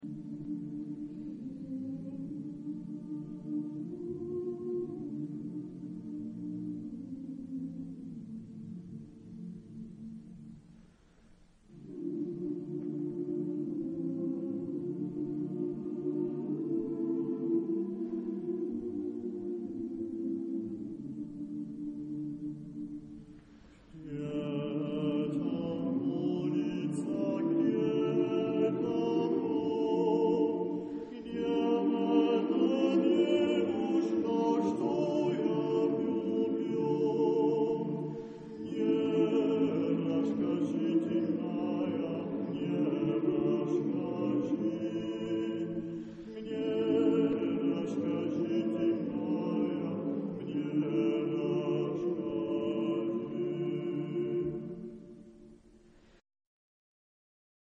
Genre-Style-Form: Contemporary ; Secular ; Popular
Type of Choir: SATBB  (5 mixed voices )
Soloist(s): Baryton (1)
Instruments: Tambourine (1)
Tonality: D minor